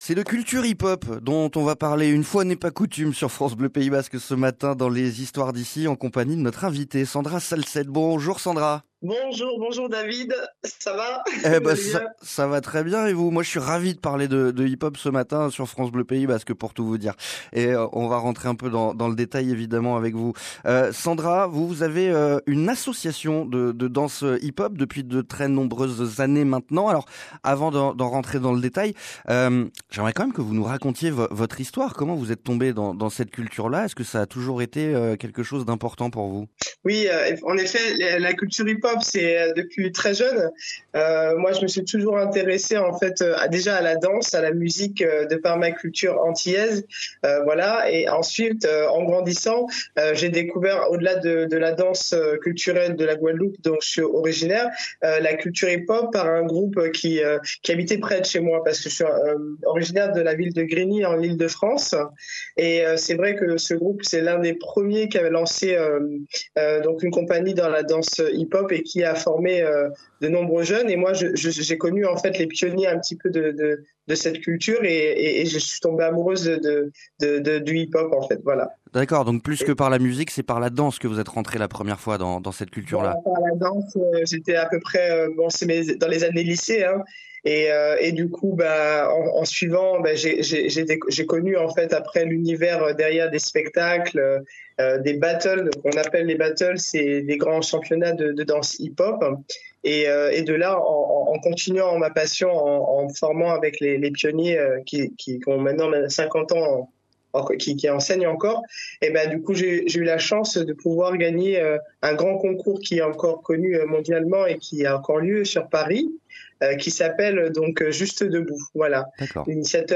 Interview : France Bleu, histoires d'ici